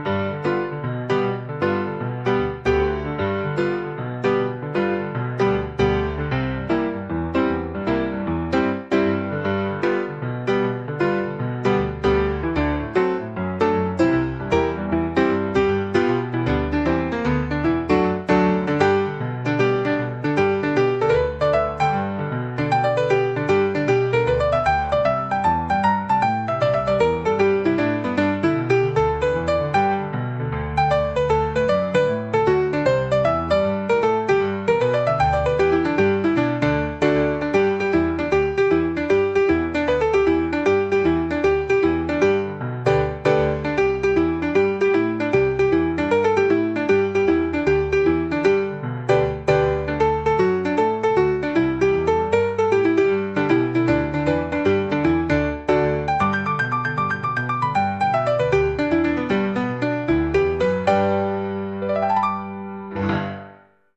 Acoustic, Blues
Happy, Playful
92 BPM